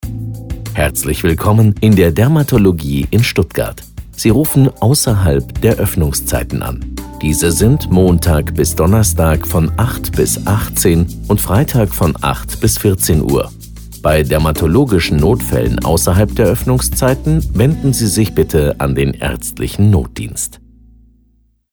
Telefonansagen mit echten Stimmen – keine KI !!!
Ansage Öffnungszeiten: